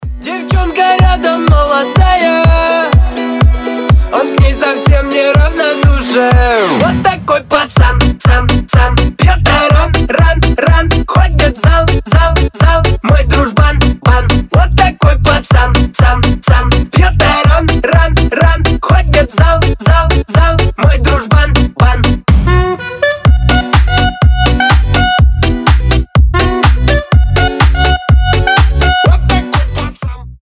гитара
позитивные
веселые
дворовые